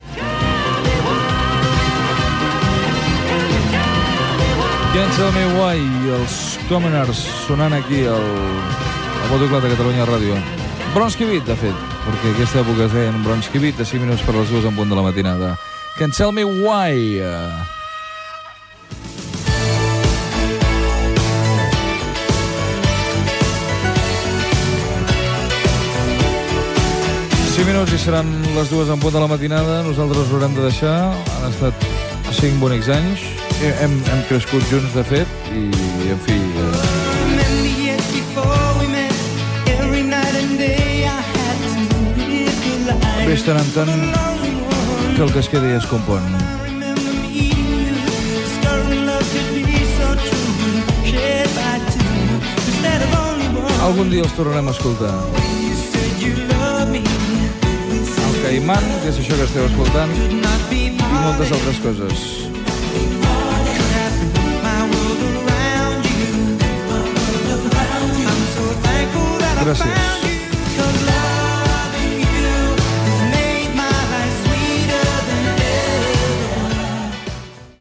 Tema musical i presentació de l'últim tema del programa.
Musical